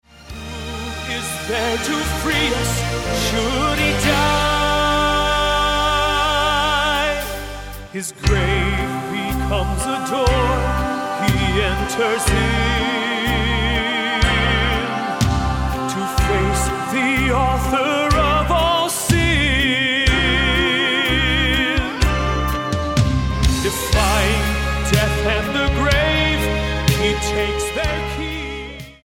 STYLE: MOR / Soft Pop